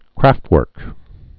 (krăftwûrk)